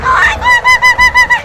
Worms speechbanks
Whatthe.wav